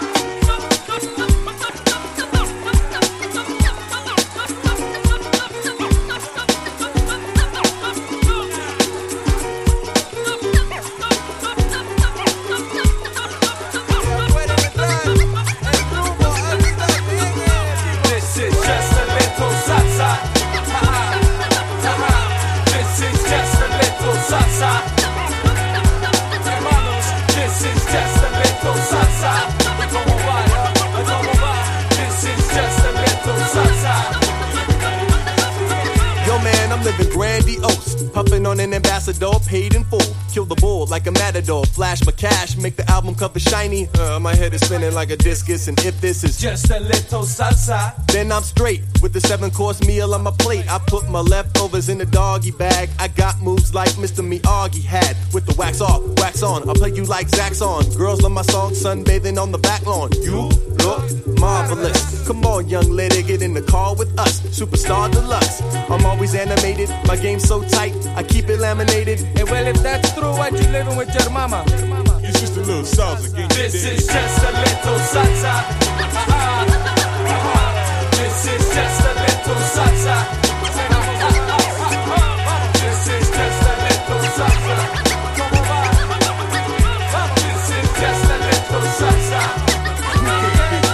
最強サンバ・ヒップホップをリミックス！